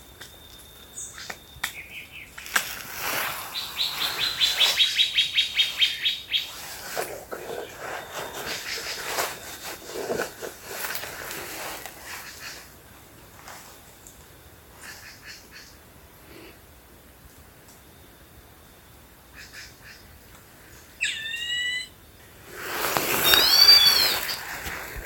Arapaçu-liso (Dendrocincla turdina)
Nome em Inglês: Plain-winged Woodcreeper
Localidade ou área protegida: Mbaracayú--Jejuimi
Condição: Selvagem
Certeza: Gravado Vocal
dendrocincla-turdina.mp3